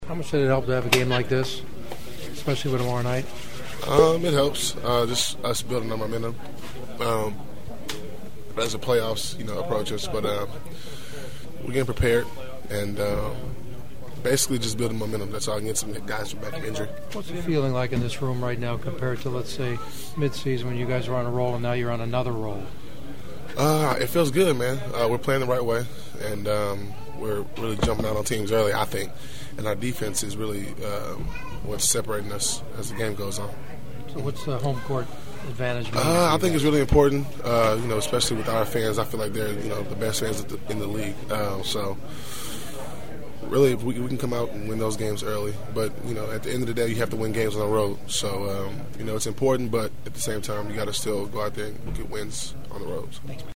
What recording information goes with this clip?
My other postgame locker room interviews…